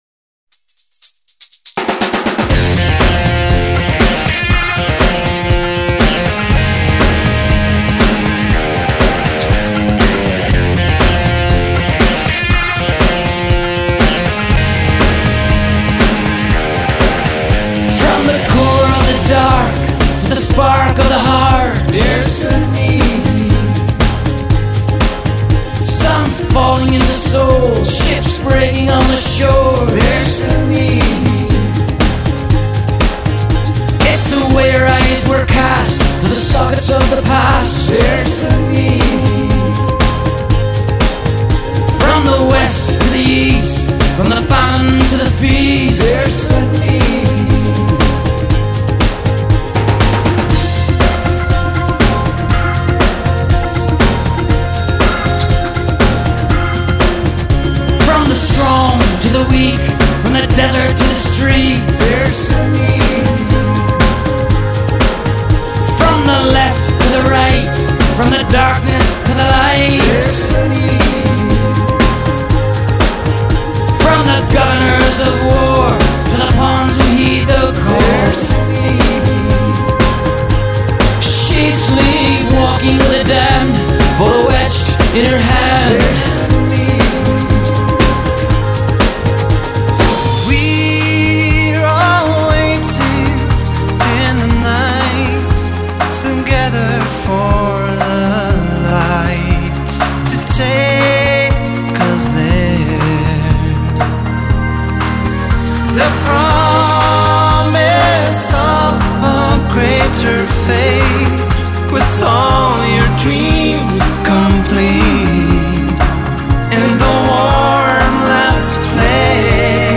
Style: Folk-Rock